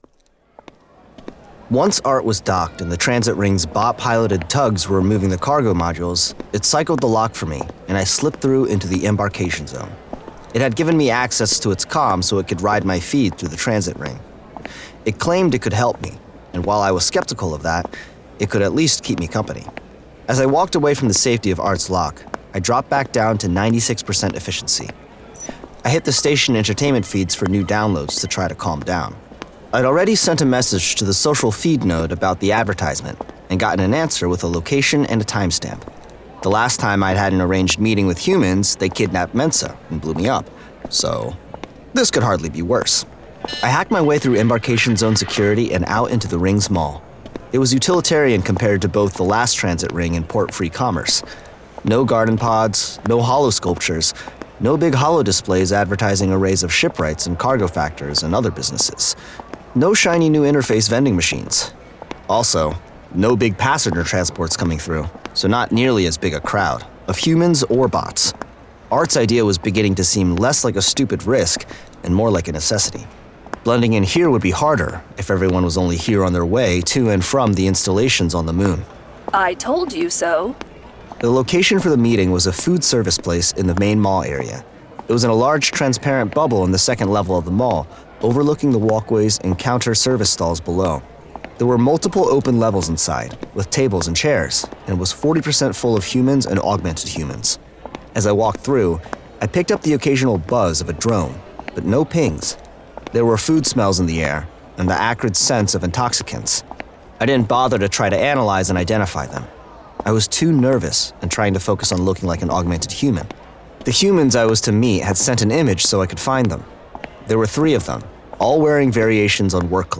Martha Wells – Artificial Condition Audiobook